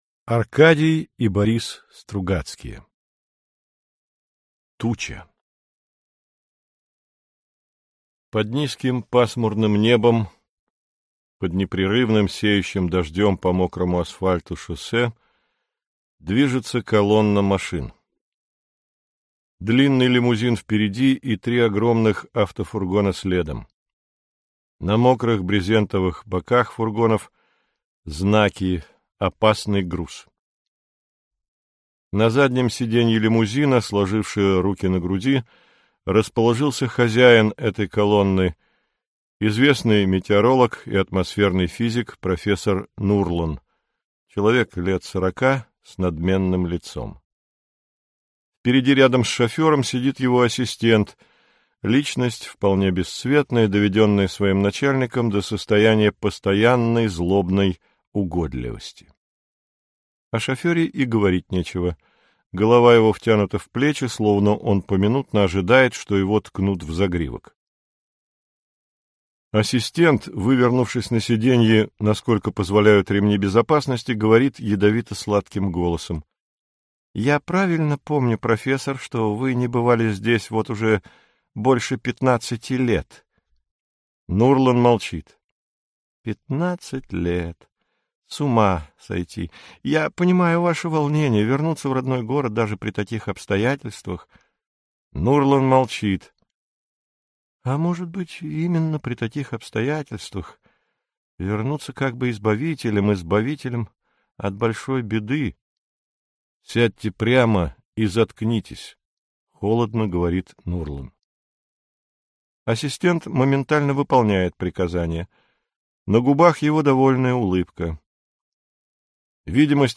Аудиокнига Туча | Библиотека аудиокниг